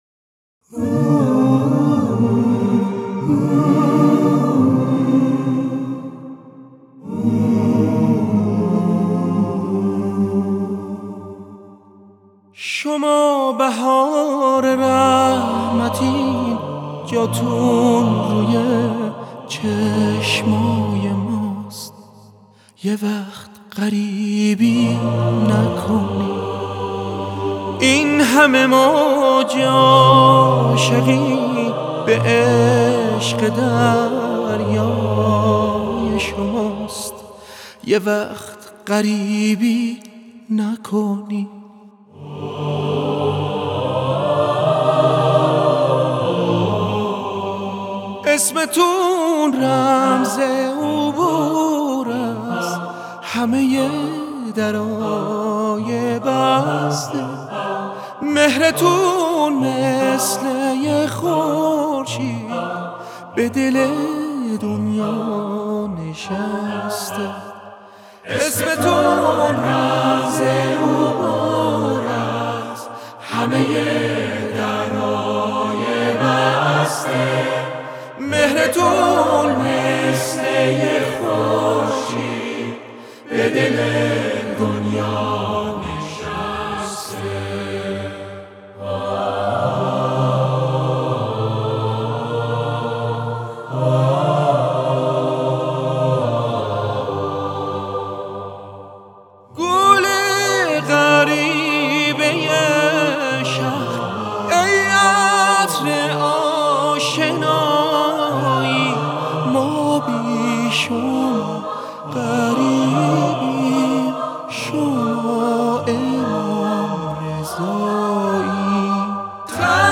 хонандаи эронӣ